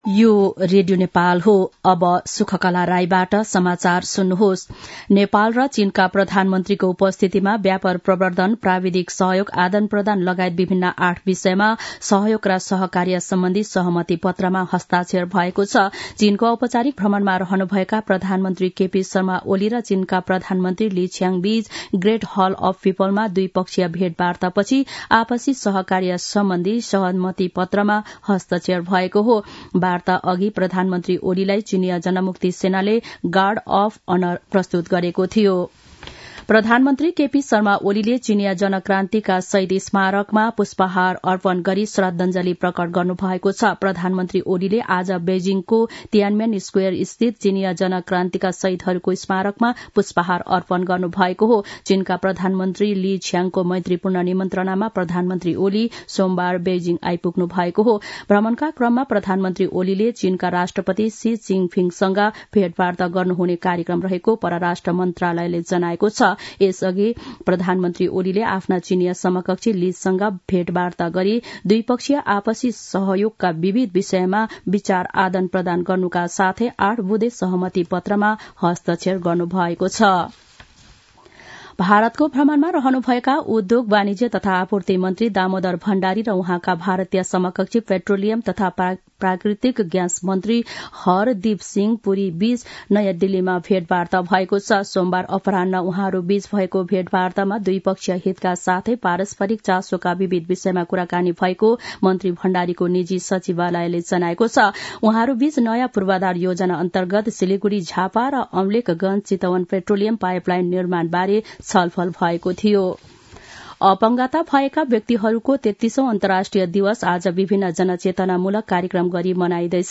दिउँसो १ बजेको नेपाली समाचार : १९ मंसिर , २०८१
1-pm-nepali-news-1-2.mp3